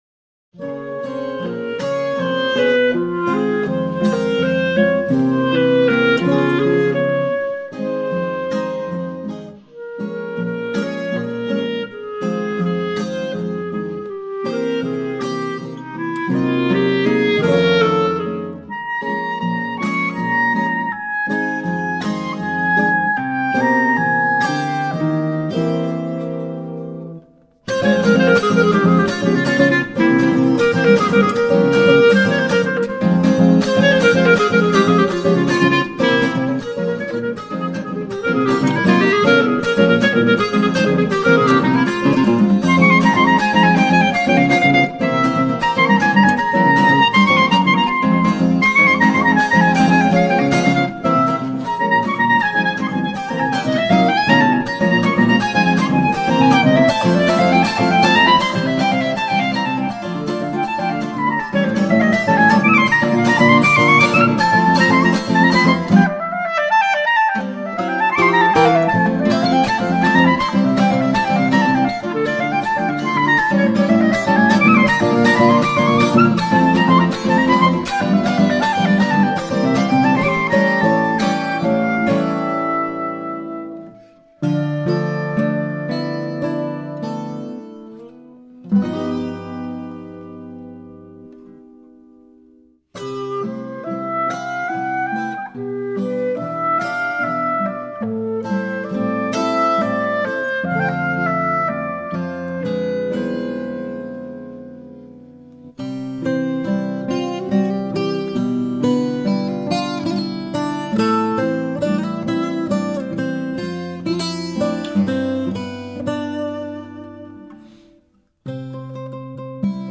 Bambuco